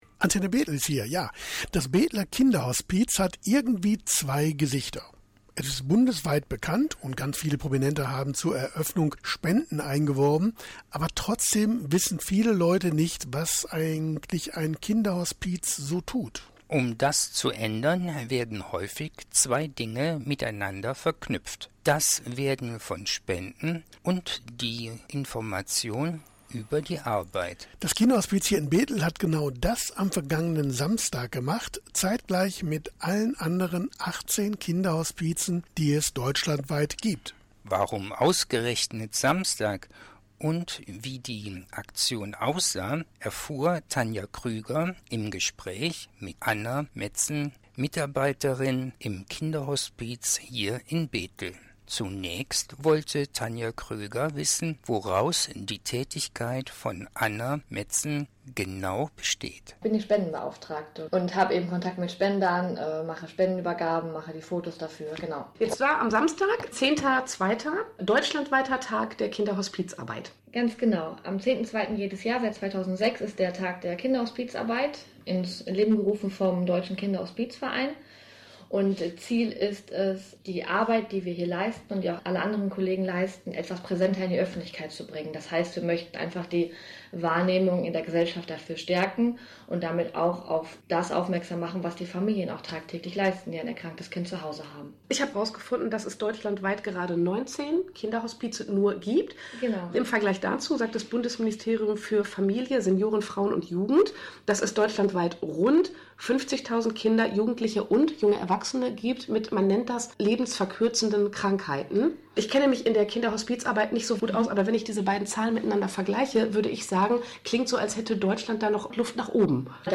Mitschnitt-Interview-Tag-der-Kinderhospizarbeit.mp3